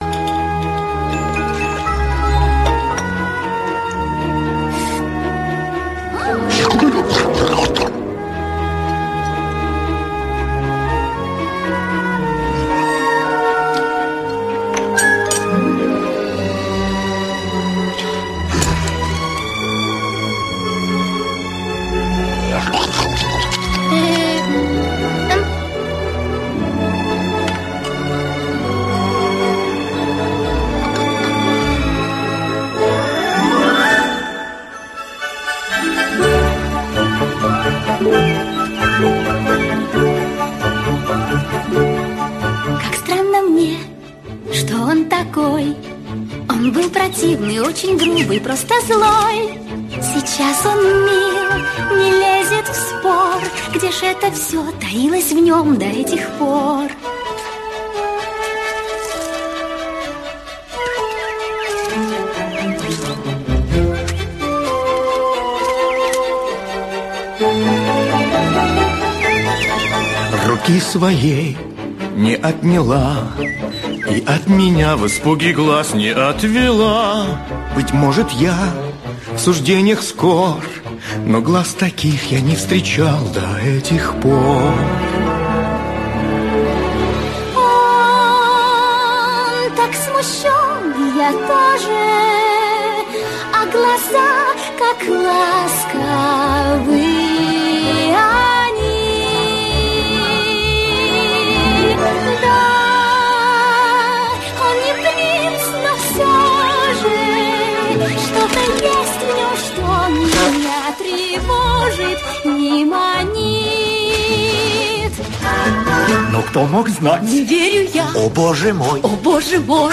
• Жанр: Детские песни
🎶 Детские песни / Песни из мультфильмов